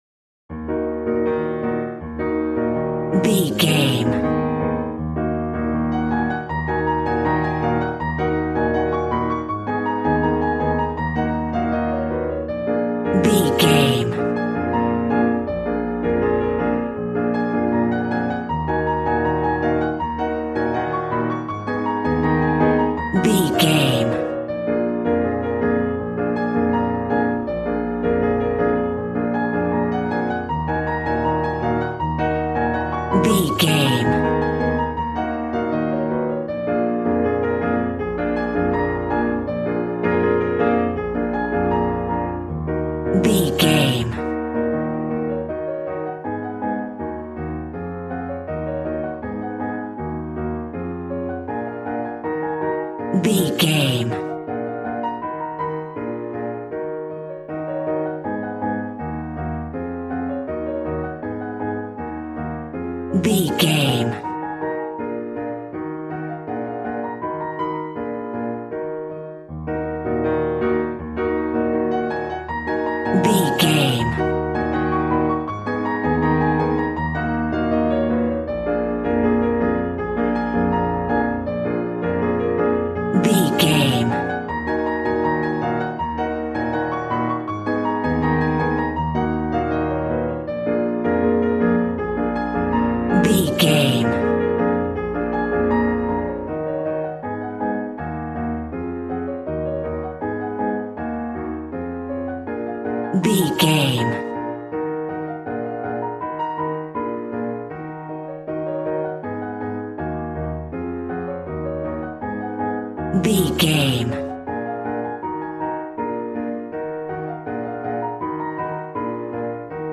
Ionian/Major
E♭
passionate
acoustic guitar